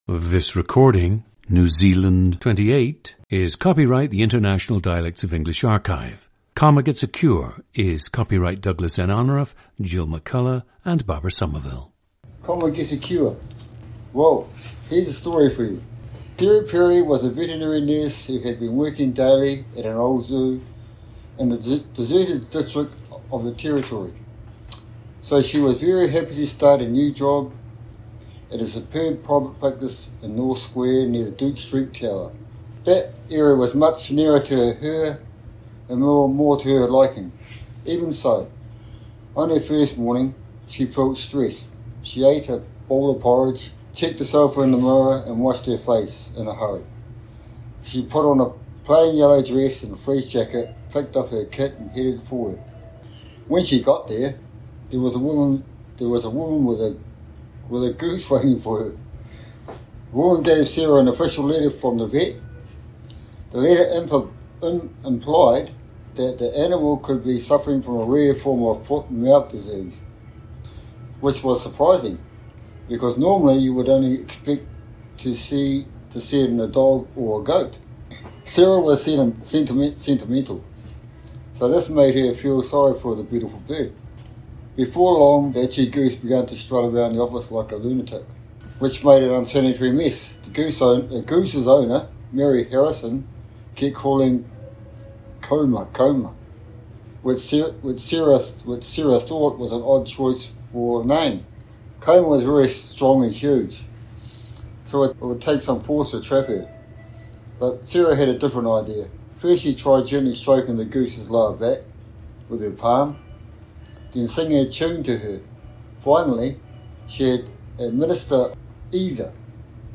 Please note that the subject uses explicit language in his unscripted speech.
PLACE OF BIRTH: Porirua, New Zealand
GENDER: male
ETHNICITY: Māori
• Recordings of accent/dialect speakers from the region you select.